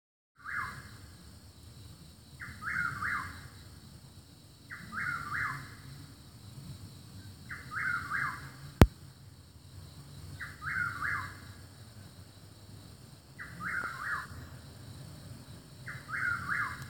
…The sound of a Whippoorwill in my back woods.